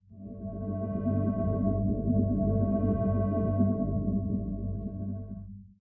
Minecraft Version Minecraft Version latest Latest Release | Latest Snapshot latest / assets / minecraft / sounds / block / beacon / ambient.ogg Compare With Compare With Latest Release | Latest Snapshot